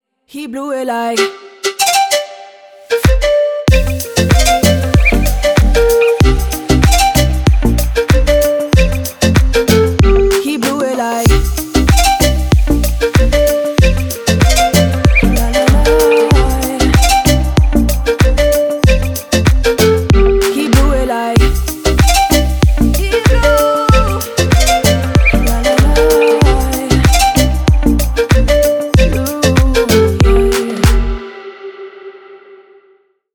• Качество: 320, Stereo
deep house
спокойные
Dance Pop
tropical house
летние
Флейта
духовые
relax